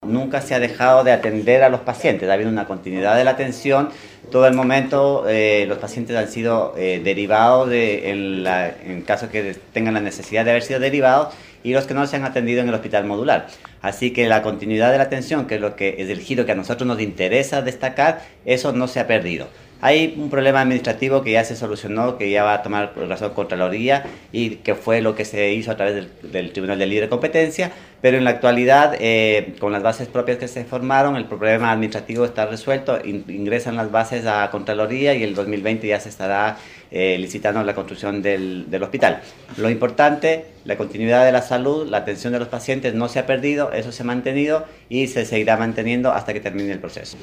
A su vez, el director (S) del Servicio de Salud Chiloé, Francisco Ricaurte, sostuvo que pese a todas las carencias en atención de salud en la comuna de Quéilen, nunca se ha dejado de atender a la población.